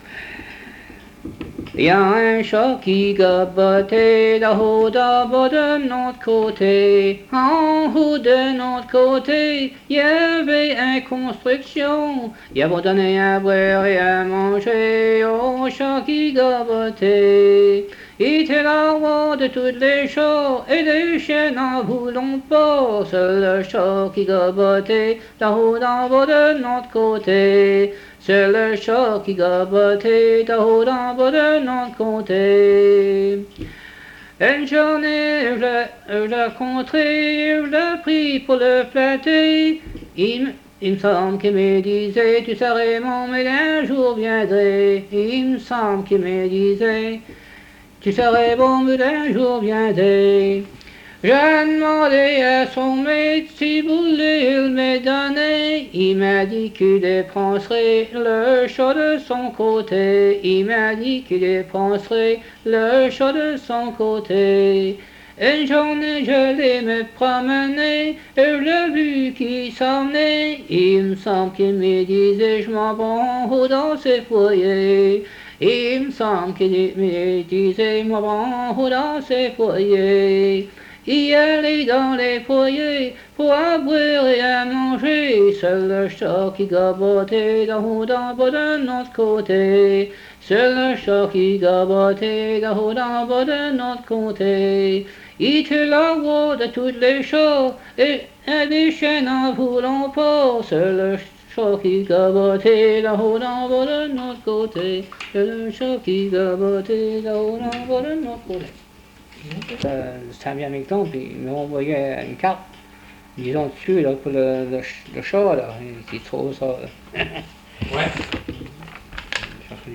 Emplacement La Grand'Terre